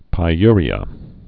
(pī-yrē-ə)